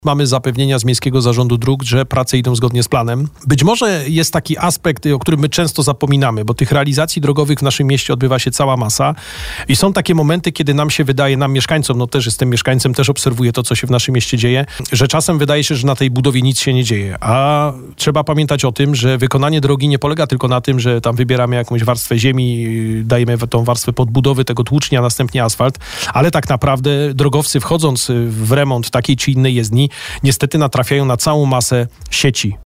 Temat związany z remontem ul. Pocztowej poruszaliśmy w trakcie porannej rozmowy.